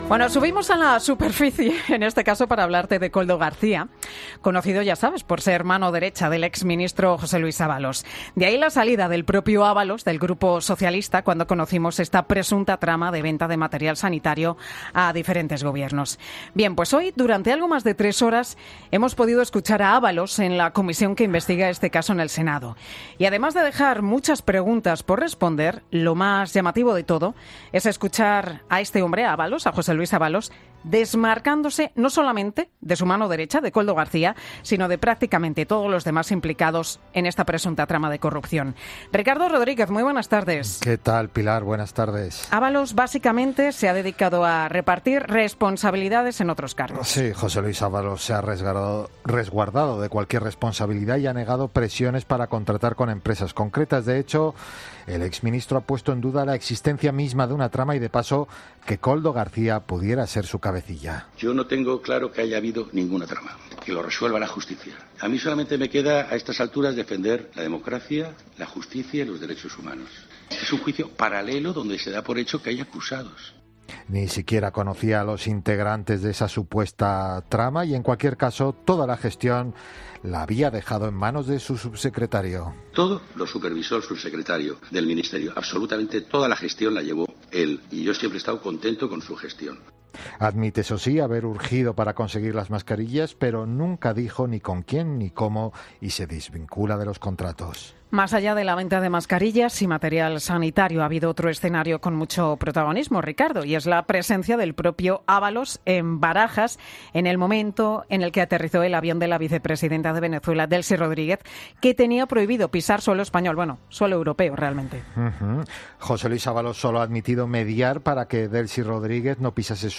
Escucha los momentos más señalados de la comparecencia de Ábalos en la comisión de investigación del Senado